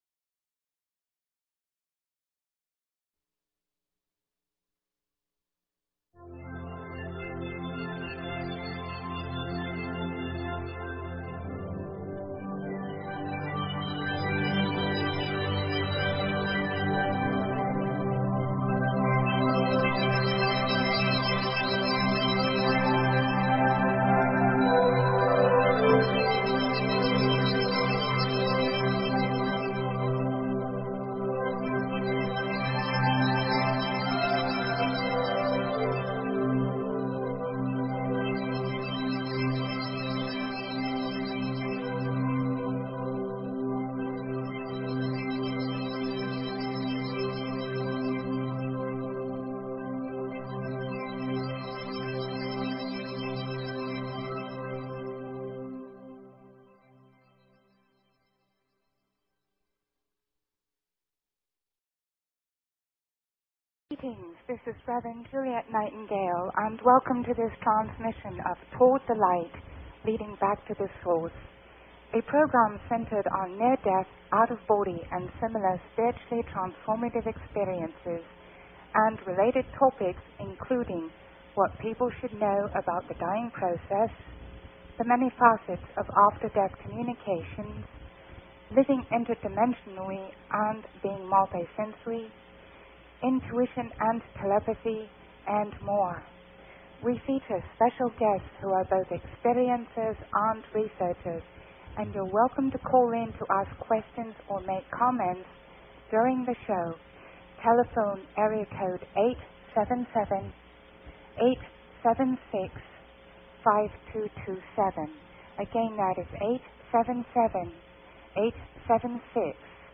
Talk Show Episode, Audio Podcast, Toward_The_Light and Courtesy of BBS Radio on , show guests , about , categorized as
She features a stellar roster of special guests who are both experiencers and researchers in the realm of NDEs and related topics.